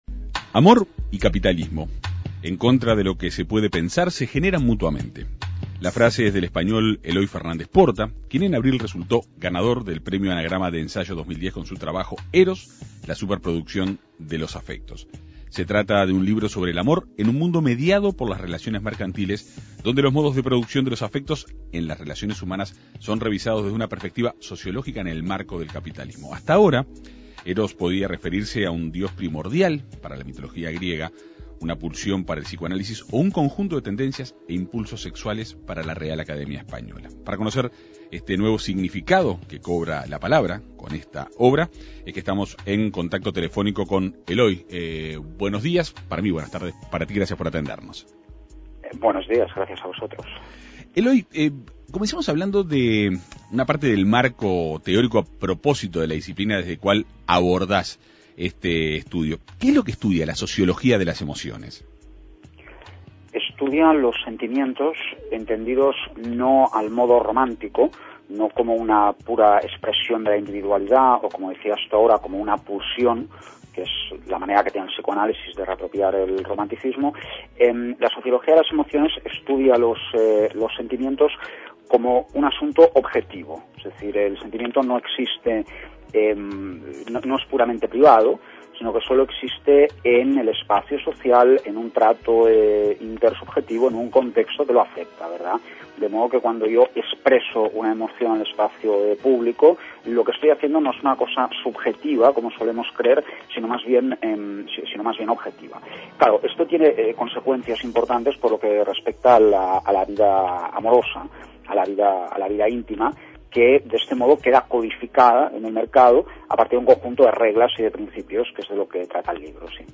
Se trata de un libro sobre el amor, en un mundo mediado por las relaciones mercantiles, donde los modos de producción de los afectos en las relaciones humanas son revisados desde una perspectiva sociológica en el marco del capitalismo. El autor fue entrevistado en la Segunda Mañana de En Perspectiva.